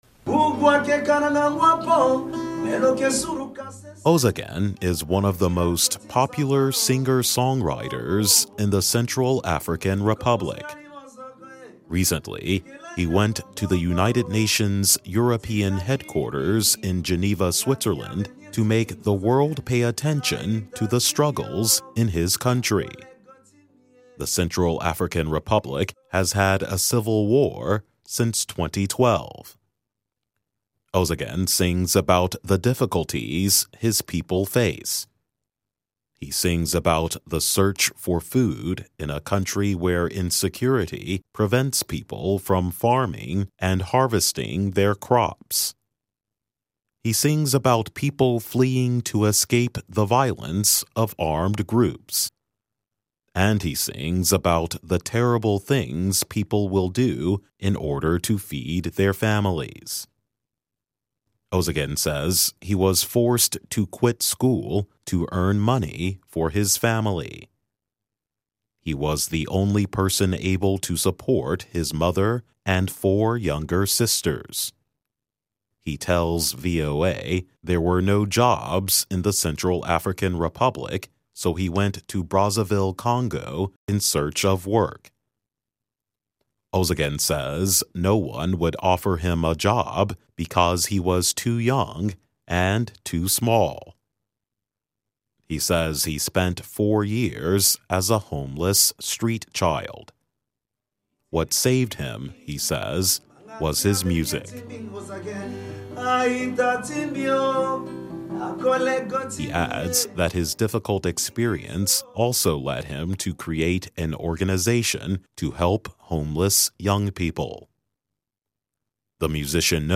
慢速英语:音乐家为中非共和国的斗争发声